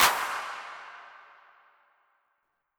Metro Claps [Background].wav